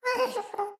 moan7.wav